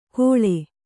♪ kōḷe